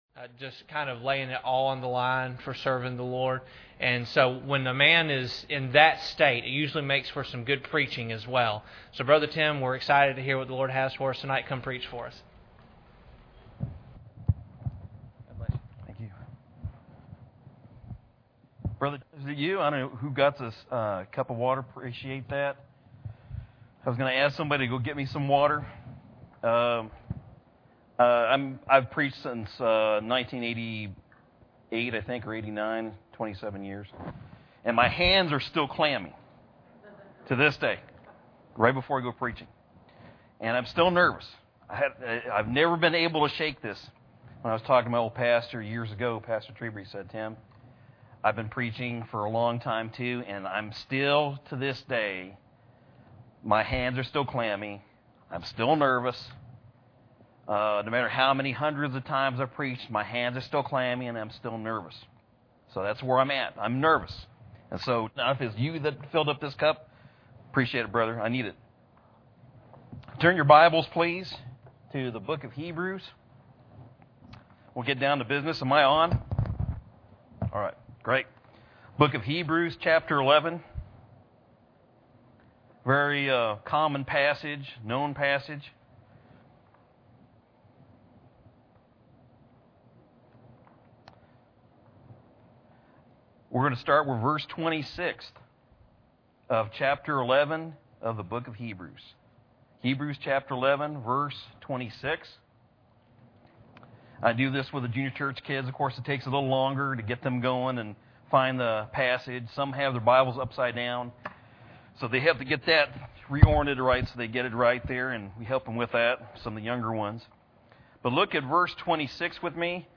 Passage: Hebrews 11:26-27 Service Type: Sunday Evening